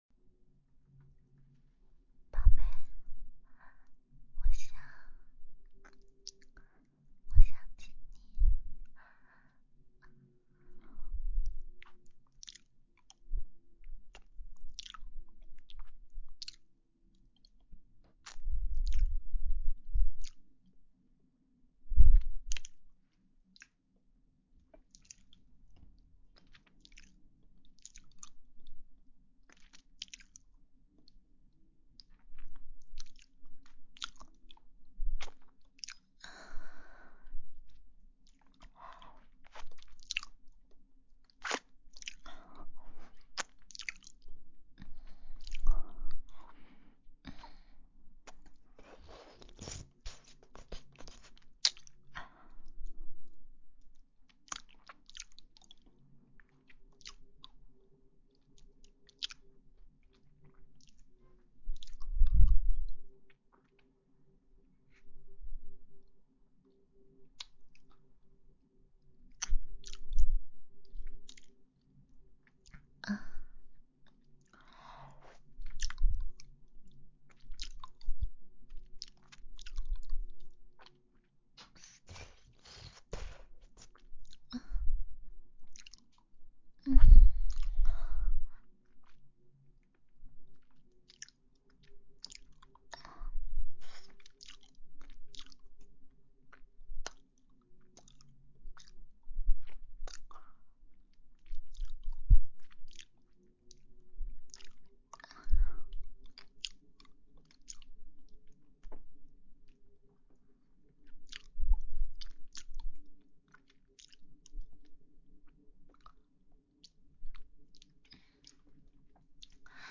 ASMR在线